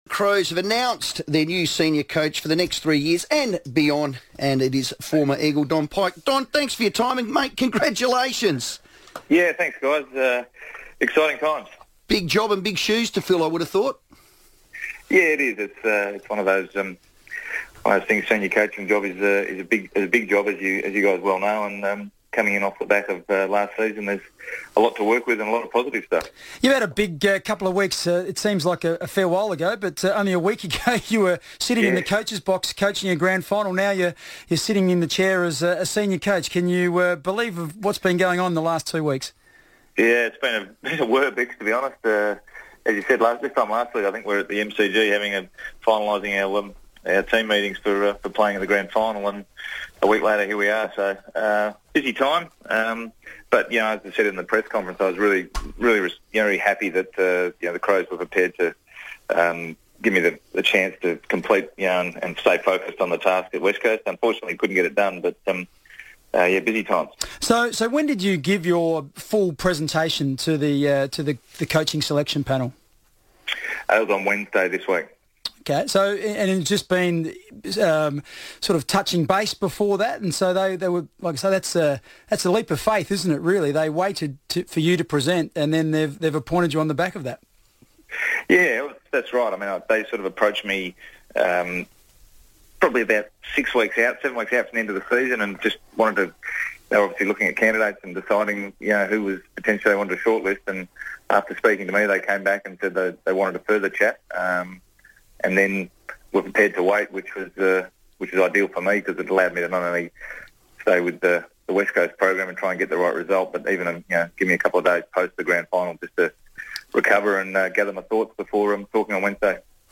Don Pyke spoke on FIVEaa radio after his appointment as Adelaide's new Senior Coach